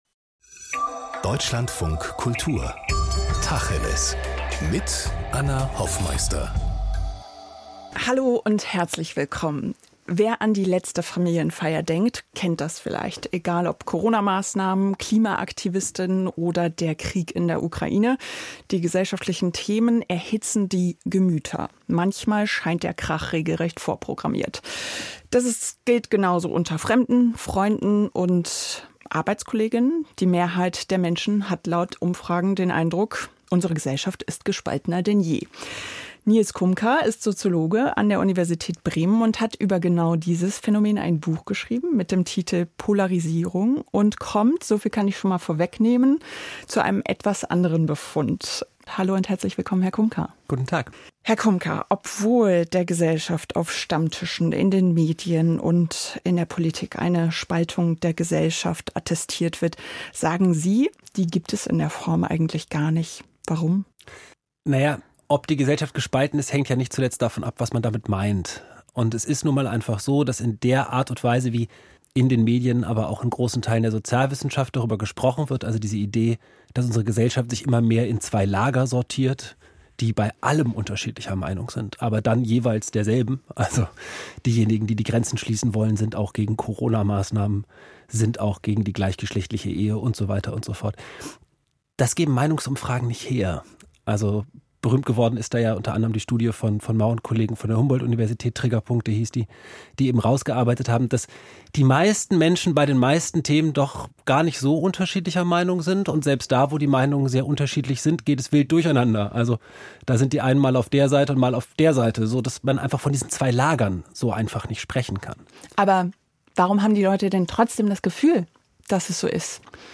Die Polarisierung der Gesellschaft bedeutet nicht nur Spaltung, sondern kann auch Orientierung bieten. Ein Gespräch